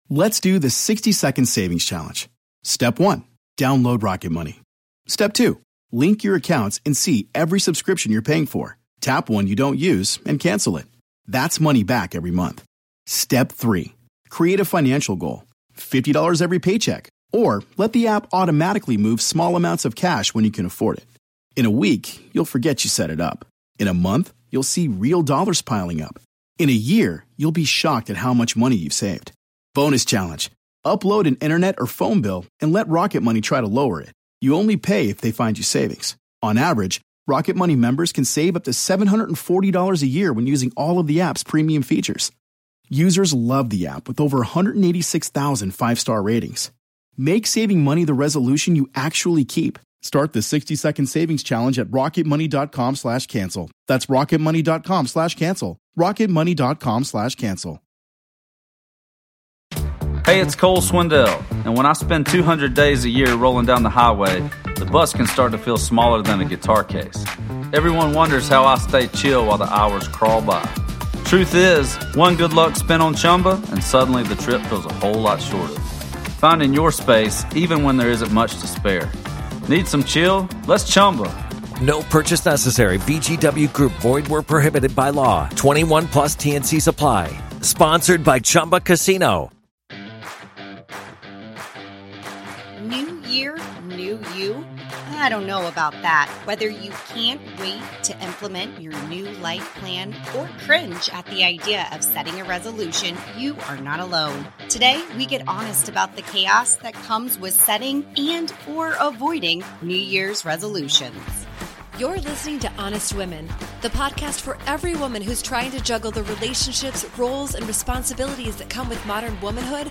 Disclaimer: Please note while this podcast features two therapists, and may feel very therapeutic, this is not therapy!